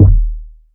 KICK.91.NEPT.wav